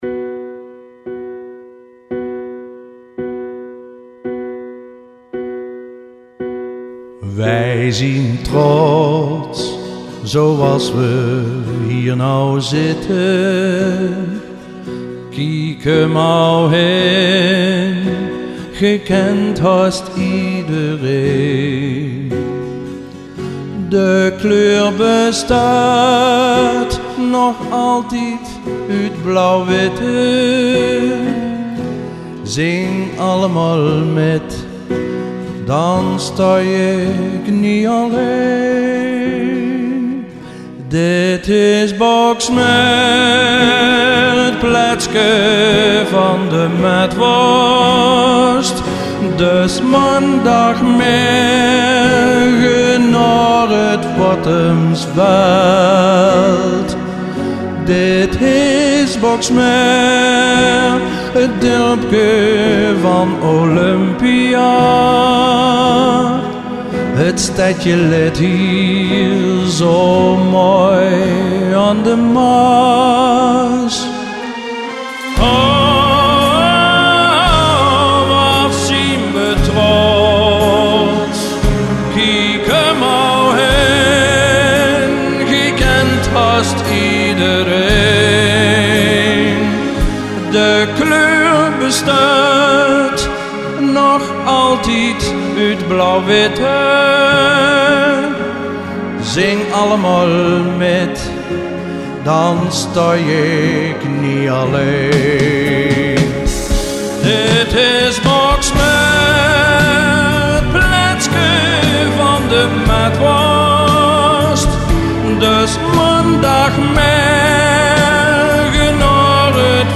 Zang